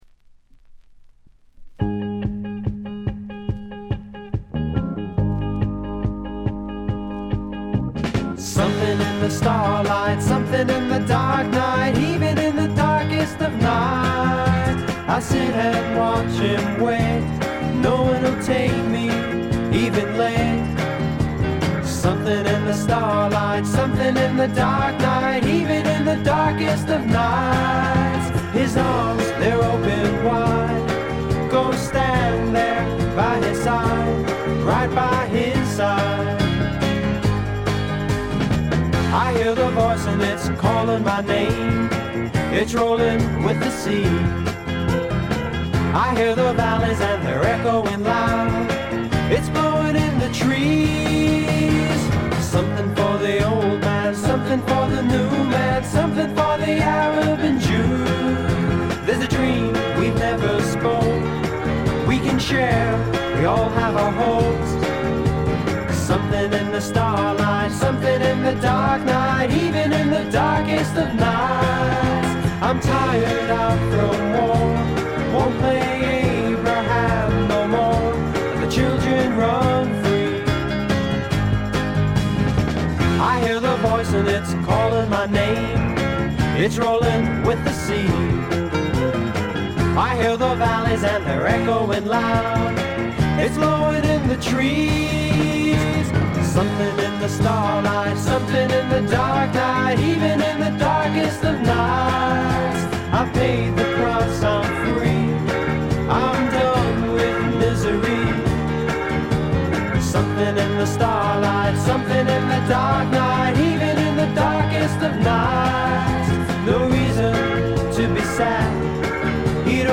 ほとんどノイズ感無し。
演奏、歌ともに実にしっかりとしていてメジャー級の85点作品。
試聴曲は現品からの取り込み音源です。